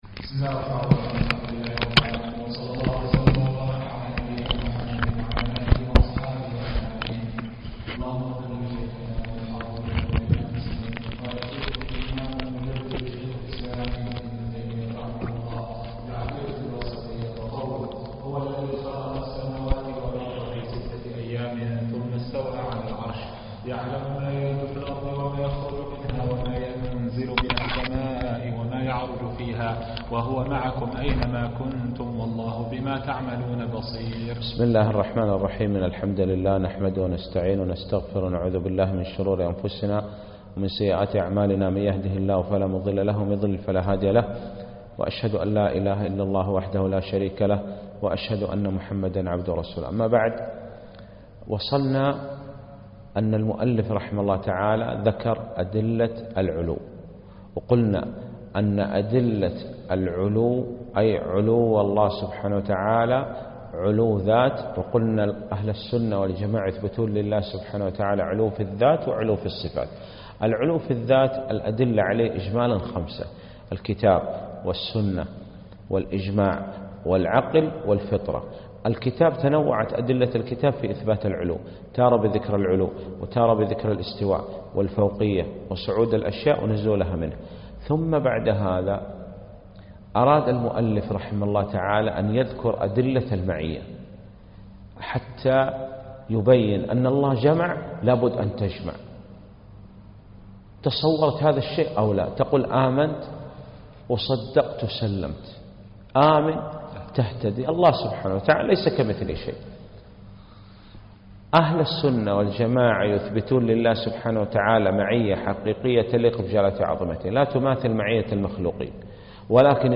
الدرس الثالث